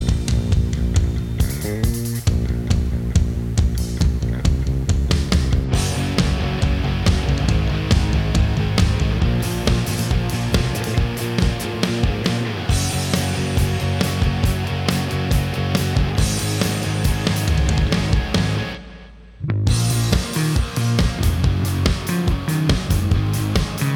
No Lead Guitar Pop (2010s) 3:20 Buy £1.50